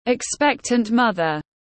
Bà bầu tiếng anh gọi là expectant mother, phiên âm tiếng anh đọc là /ɪkˈspek.tənt ˈmʌð.ər/.